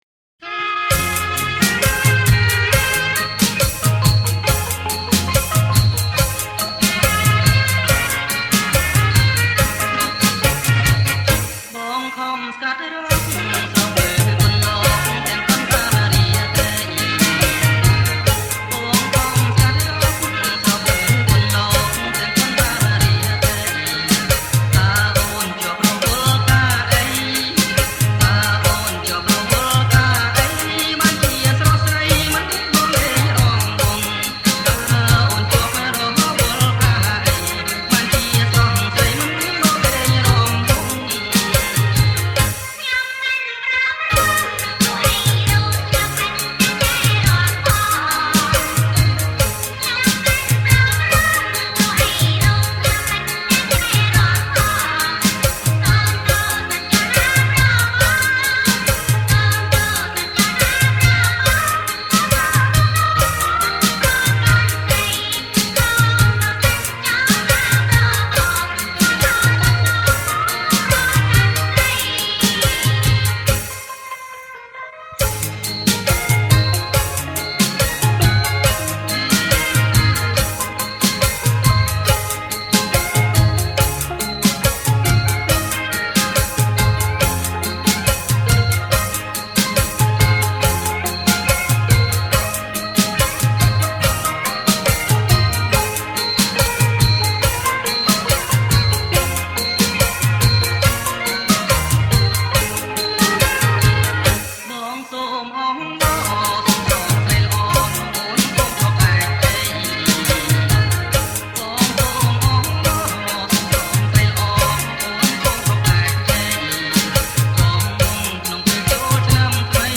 ប្រគំជាចង្វាក់ រាំវង់